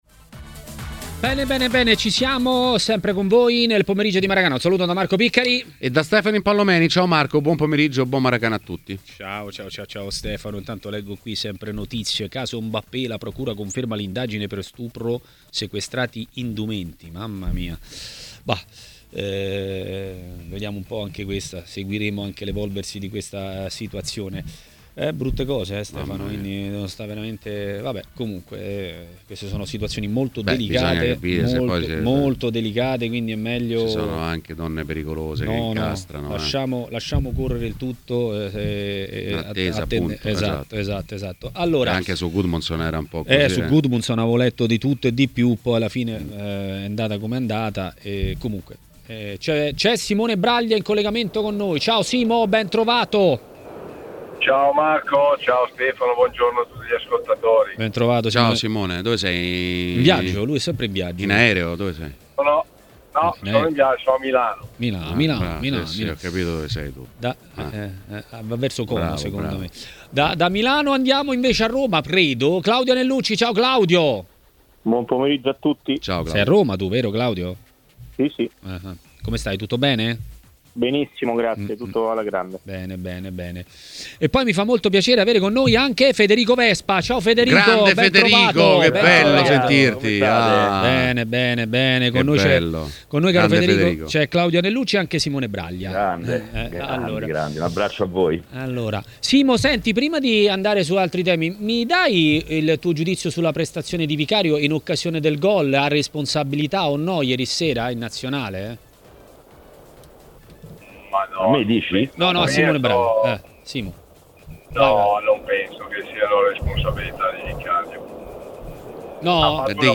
A intervenire in diretta a Maracanà, nel pomeriggio di TMW Radio, è stato l'ex portiere Simone Braglia.